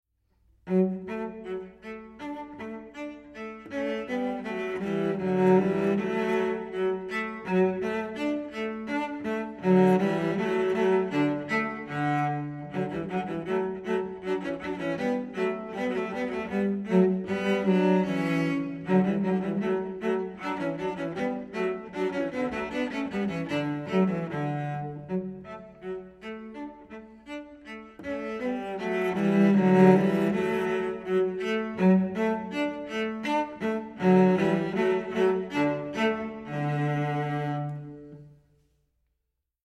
Voicing: Cello Duet